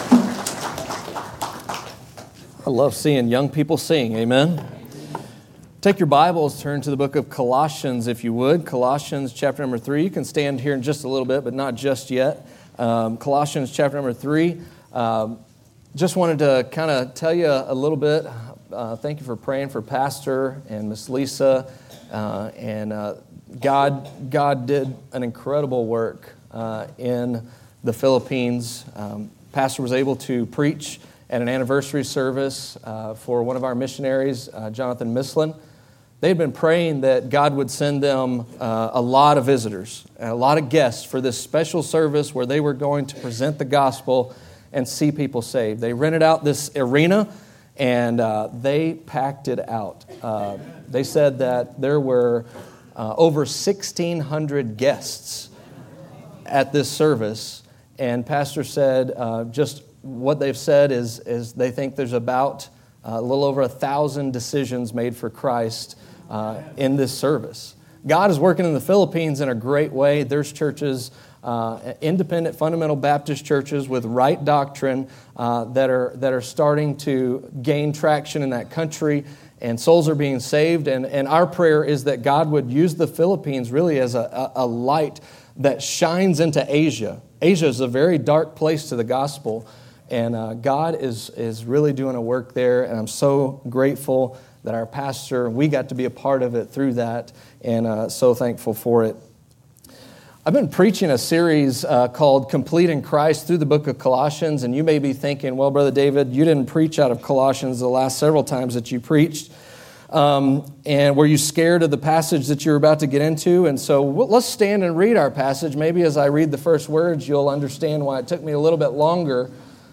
Download Watch Listen Details Share From Series: " Colossians: Complete In Christ " Preaching Through the Book of Colossians.